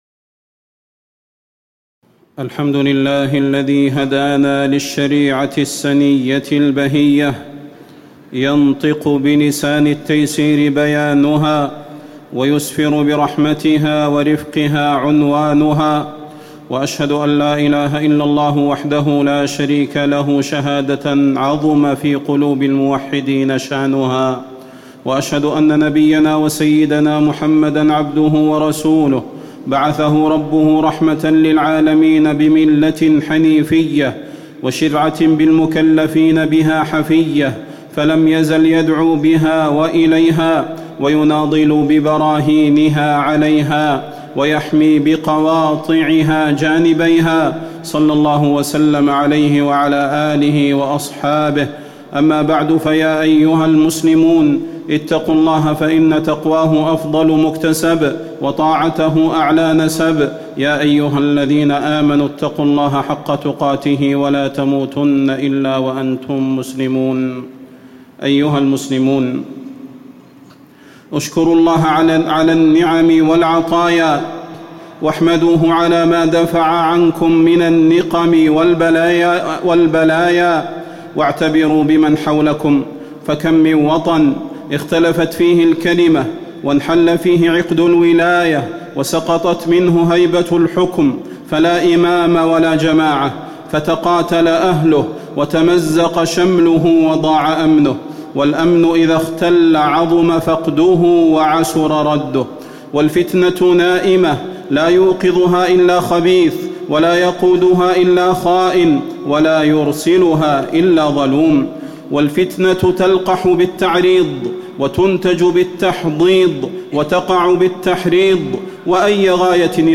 تاريخ النشر ١٣ محرم ١٤٣٨ هـ المكان: المسجد النبوي الشيخ: فضيلة الشيخ د. صلاح بن محمد البدير فضيلة الشيخ د. صلاح بن محمد البدير حكم الخروج على حكام المسلمين